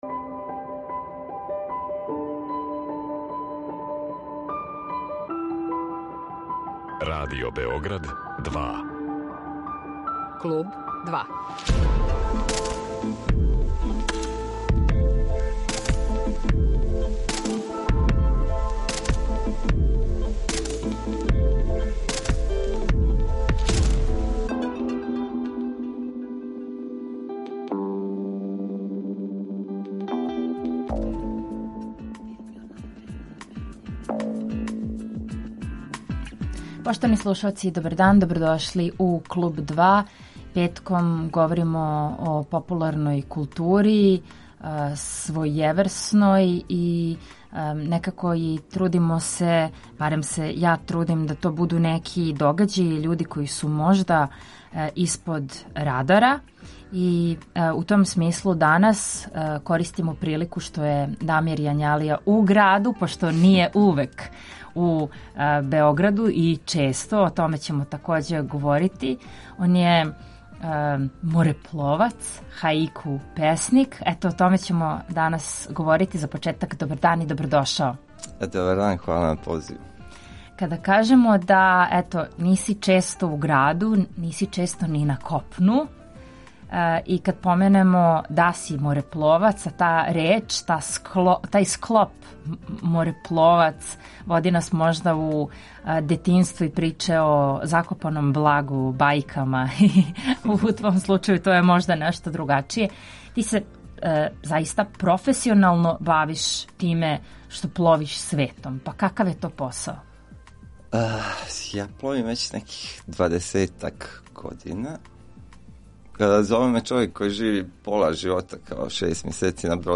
читати његове песме, али и разговарати о пространствима, дамарима, зену и дисторзијама.